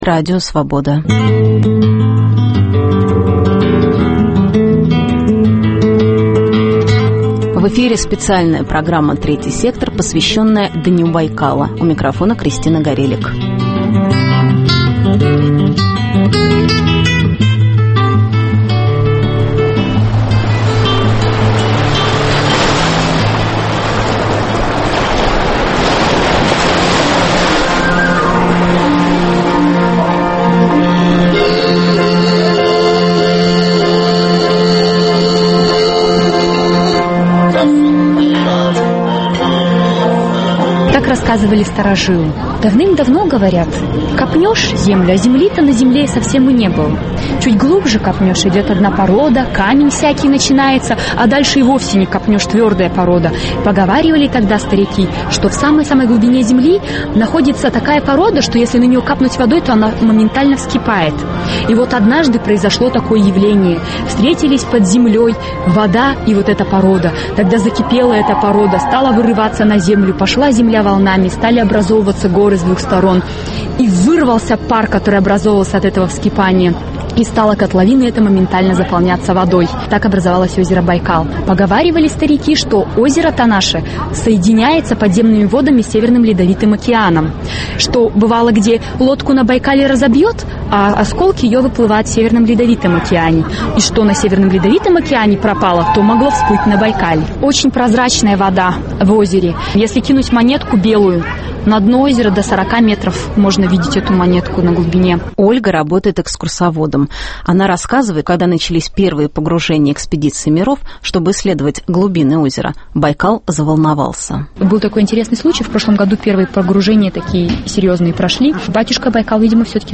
Есть ли выход из порочного круга – этому будет посвящена специальная программа, подготовленная совместно с Программой Развития ООН. В программе участвуют жители бурятских сел, главы местных администраций, бурятские экологи и представители местных общественных организаций.